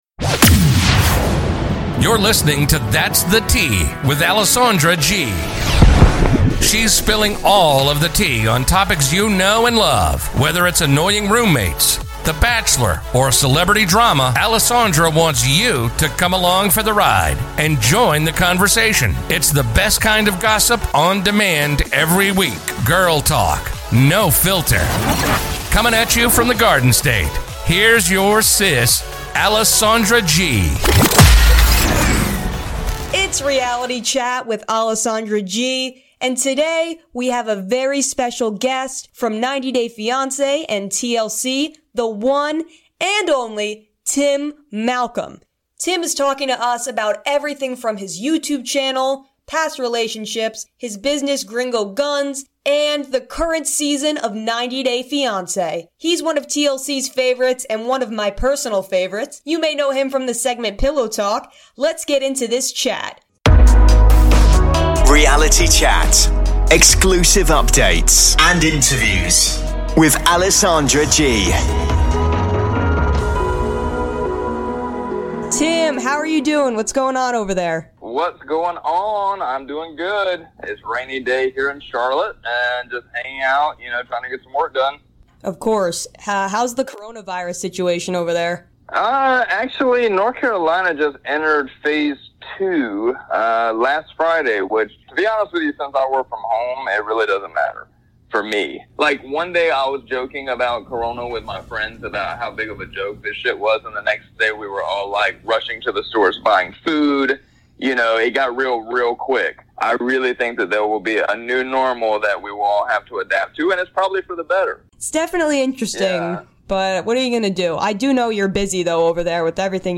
exclusive interviews and updates on reality tv.